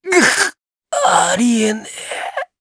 Neraxis-Vox_Dead_jp.wav